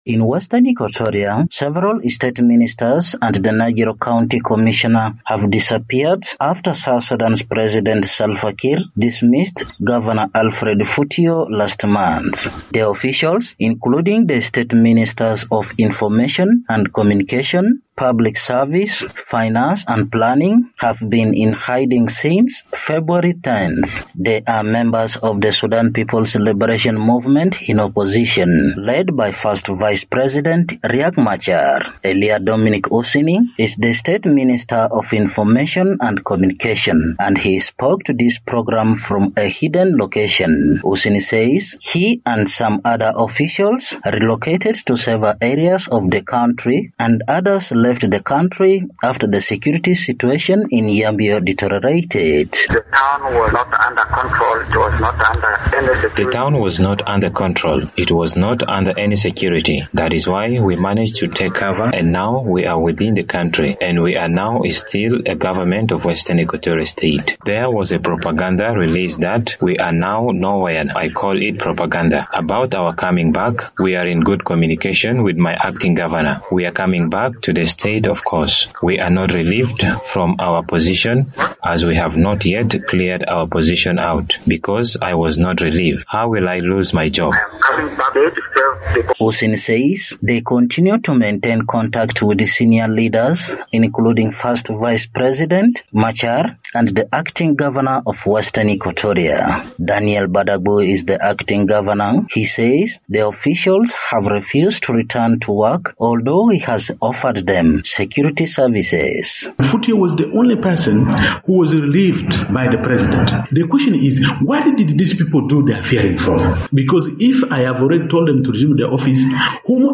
The Sudan People’s Liberation Movement in Opposition (SPLM-IO) in Western Equatoria State denies allegations that opposition-appointed ministers have abandoned their duties. It says they are not in Yambio because of safety concerns following a growth of violence and attacks on its members. For VOA news.